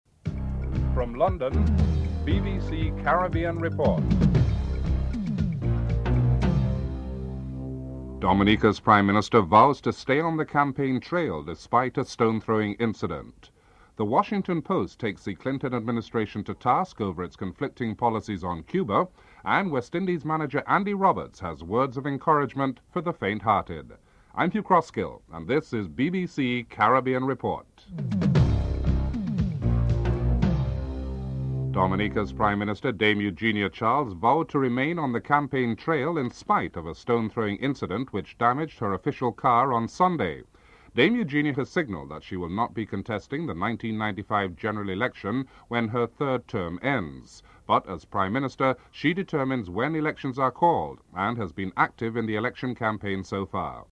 8. Interview with West Indies team manager Andy Roberts (11:53-14:55)